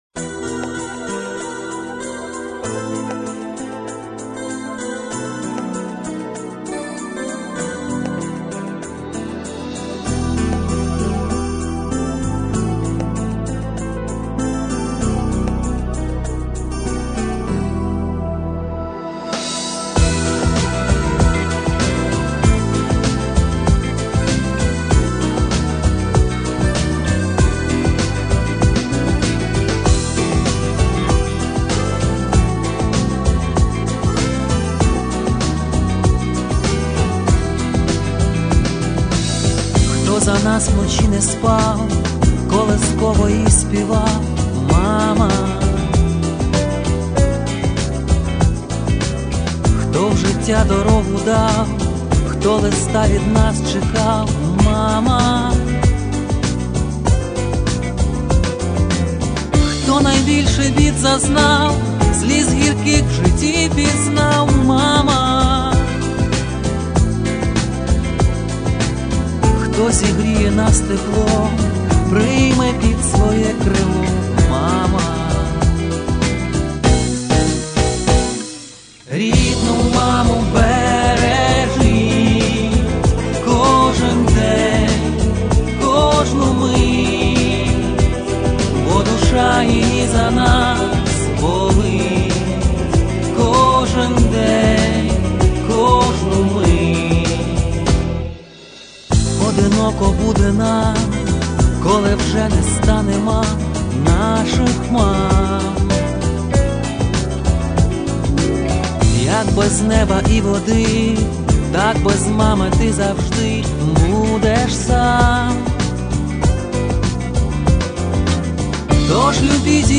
Ніжна пісня